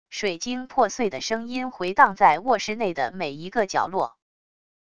水晶破碎的声音回荡在卧室内的每一个角落wav音频